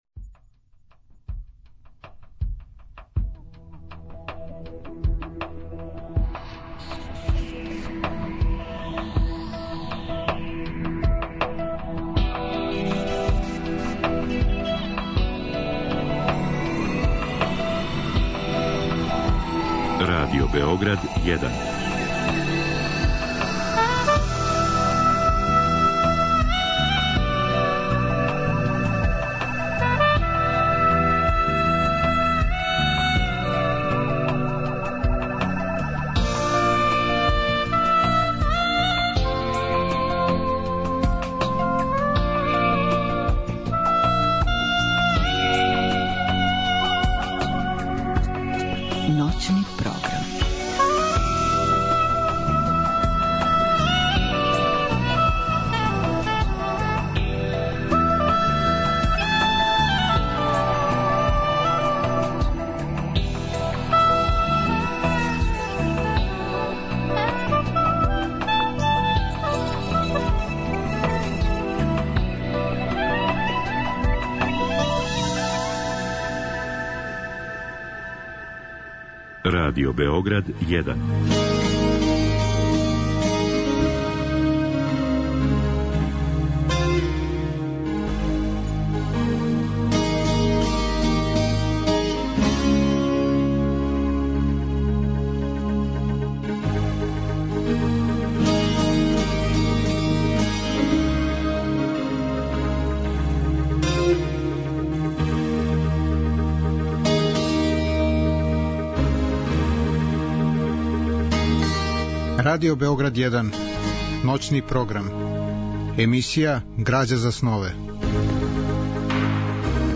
Разговор и добра музика требало би да кроз ову емисију и сами постану грађа за снове.
У трећем сату емисије слушаћемо песме Милоша Црњанског, у казивању самог аутора, а у четвртом сату емисије слушаћемо размишљања и сећања Милоша Црњанског, у којима велики писац говори о себи, свом животу и књижевном раду.